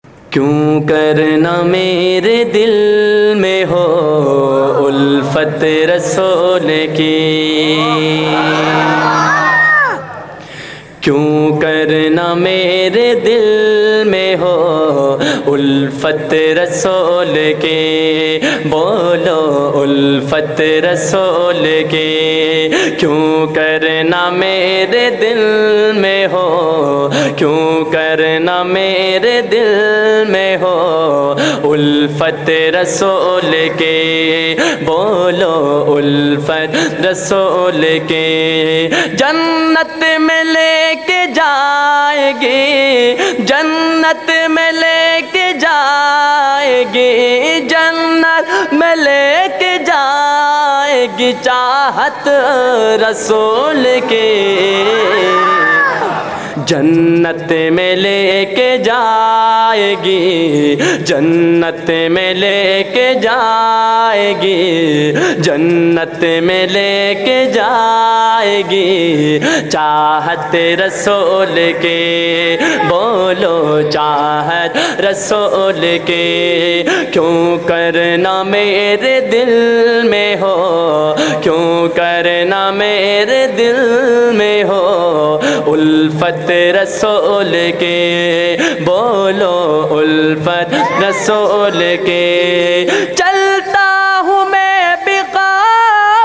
Heart Touching Naat